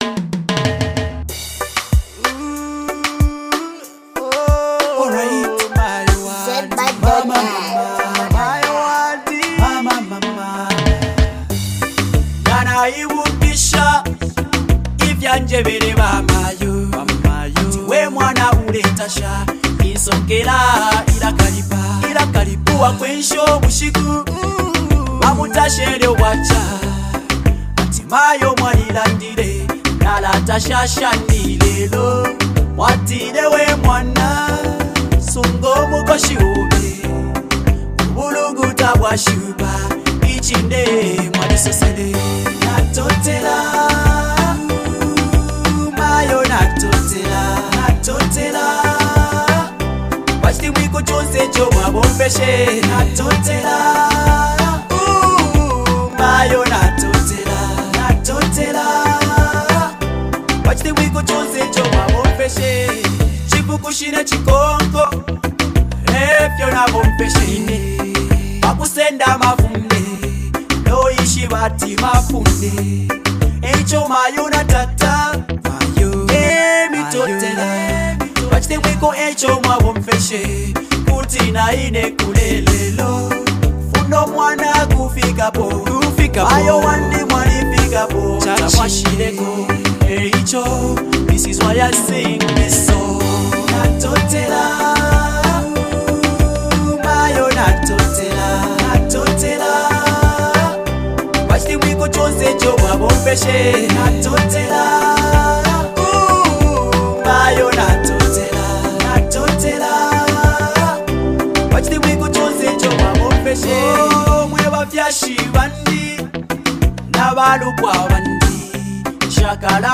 bringing a warm and genuine energy to the lyrics.